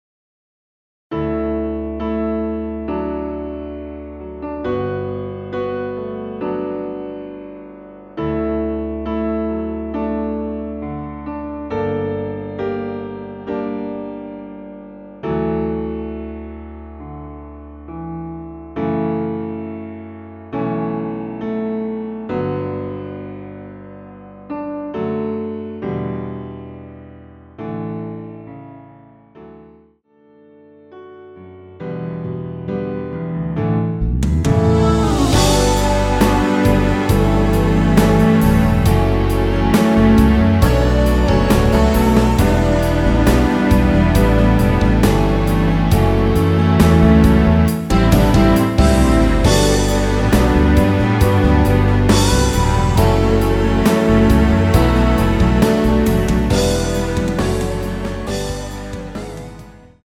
원키에서(-4)내린 MR입니다.
앞부분30초, 뒷부분30초씩 편집해서 올려 드리고 있습니다.
중간에 음이 끈어지고 다시 나오는 이유는